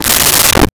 Desk Drawer Opened 01
Desk Drawer Opened 01.wav